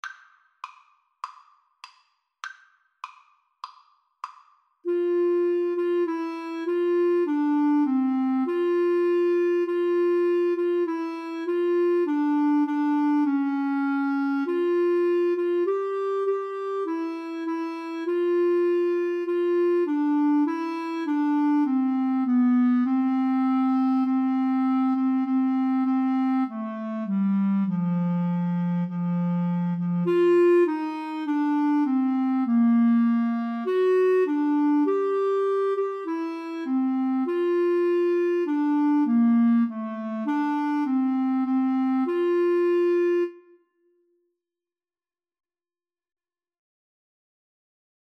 F major (Sounding Pitch) G major (Clarinet in Bb) (View more F major Music for Clarinet-Violin Duet )
4/4 (View more 4/4 Music)
Classical (View more Classical Clarinet-Violin Duet Music)